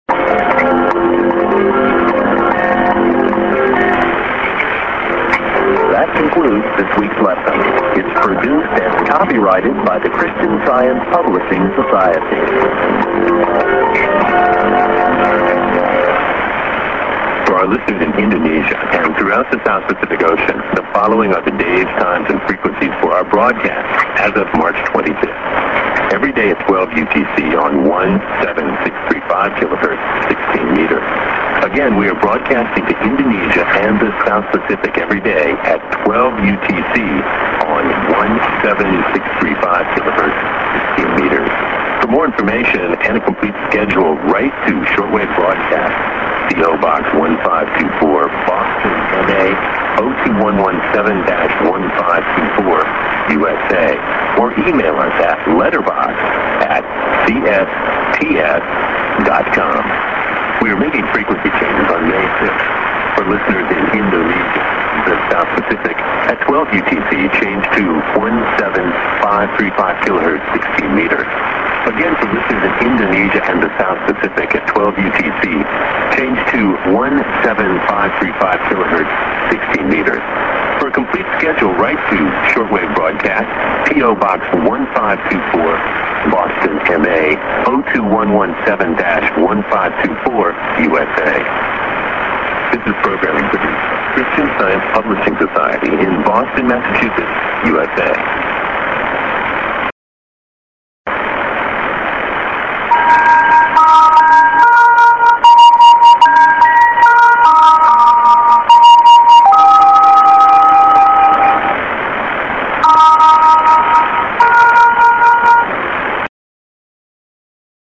End ID+SKJ+ADDR(post)(man)-> s/off ->miss IS:VOR s/off | via Khabarovsk Russia